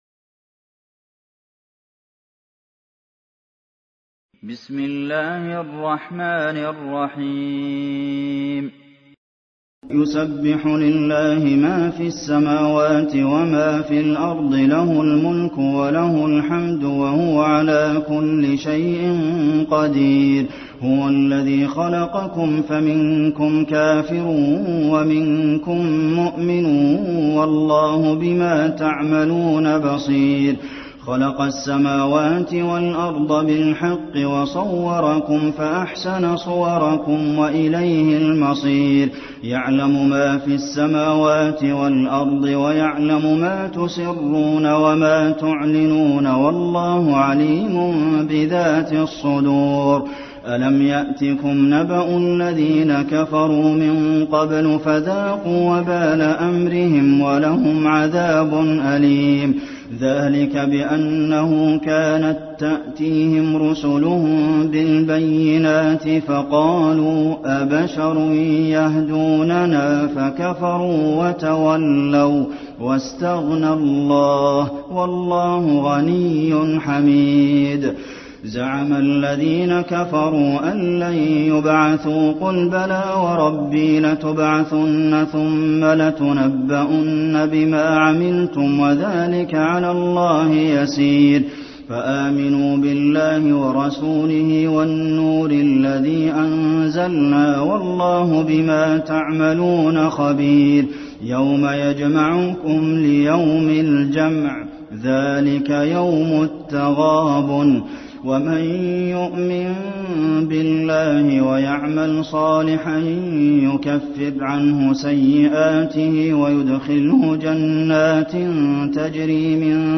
المكان: المسجد النبوي التغابن